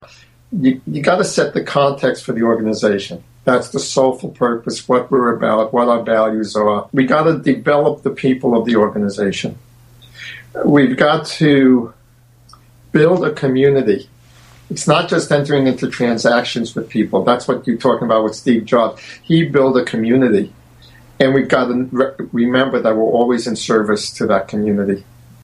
From the radio show THE PILGRIM ON THE 405